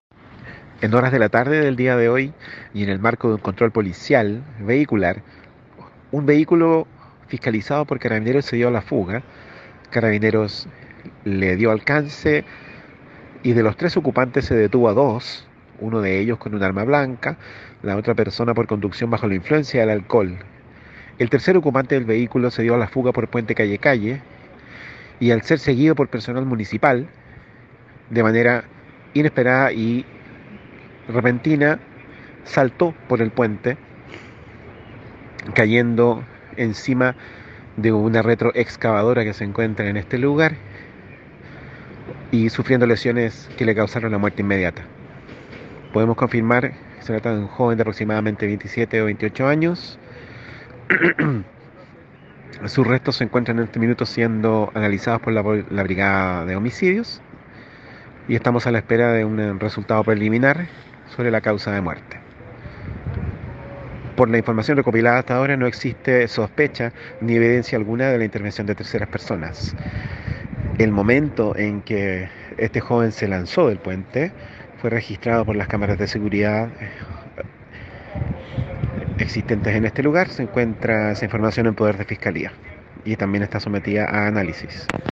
Audio del fiscal Álvaro Pérez sobre la muerte de un hombre que saltó desde el puente Calle Calle y cayó sobre una retroexcavadora.